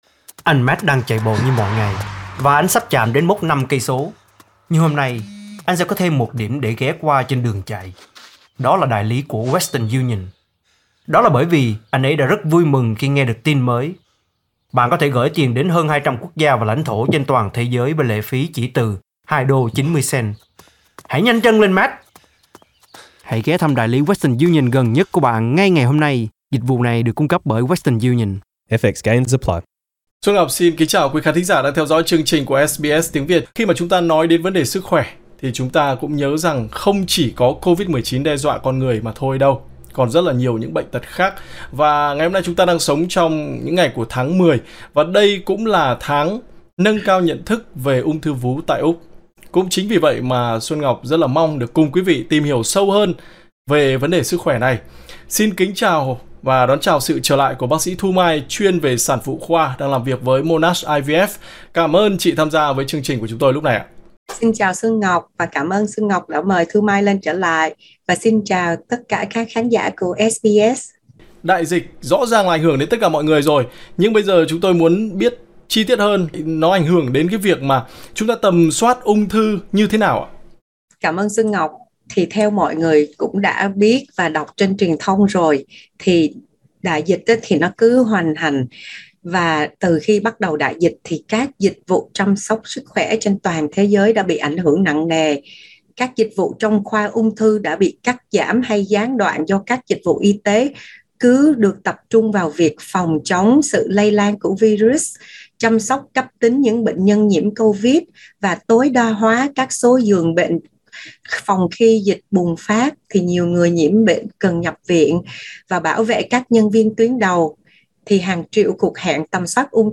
Một số vấn đề chính trong cuộc phỏng vấn: Đại dịch đang ảnh hưởng đến tầm soát ung thư theo cách nào?